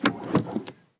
Palanca de cambios de un coche Golf 2
Sonidos: Transportes